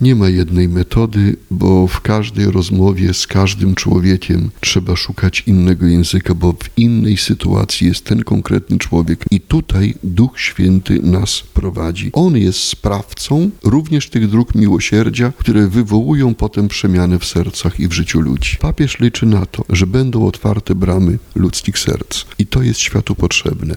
Byłem uczestnikiem wielu dyskusji, w których ludzie podkreślali swoją otwartość, ale pod jednym warunkiem – mówił na naszej antenie arcybiskup metropolita Andrzej Dzięga.